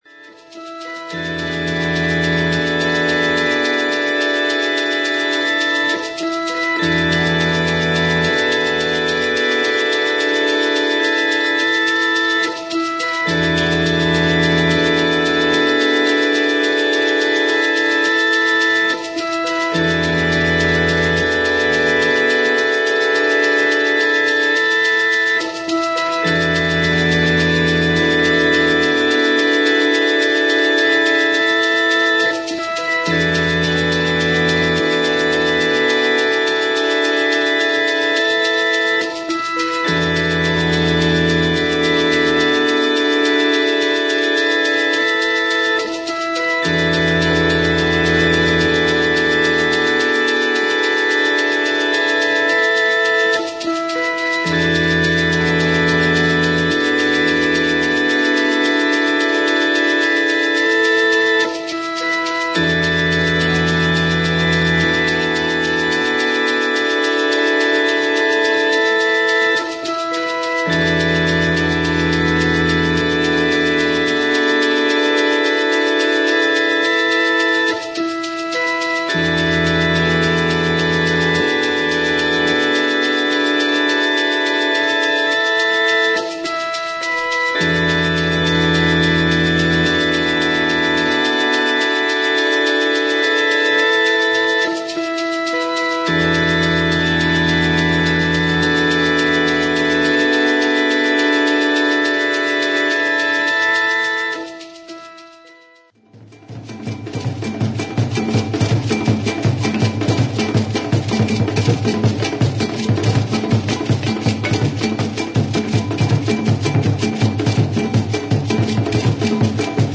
Live In NYC November 1969